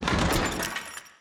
SFX_Motorcycle_PickUp_02.wav